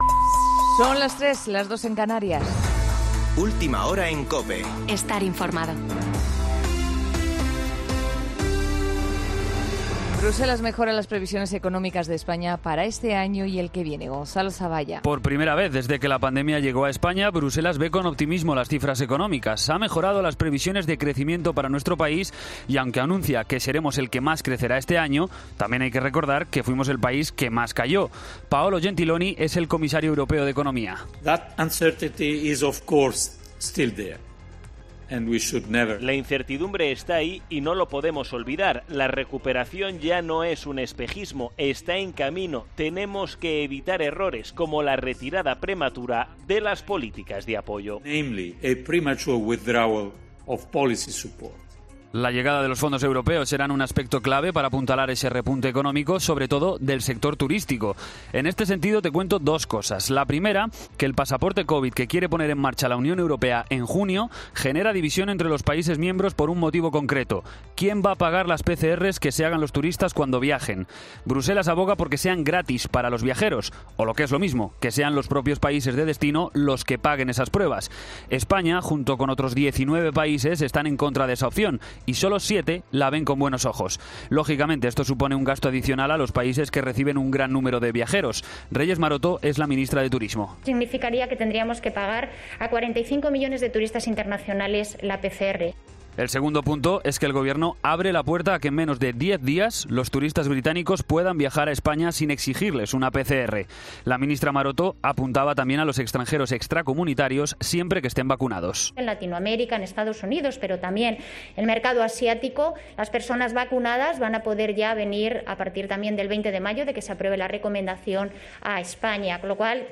Boletín de noticias COPE del 13 de mayo de 2021 a las 03.00 horas
AUDIO: Actualización de noticias ‘Herrera en COPE’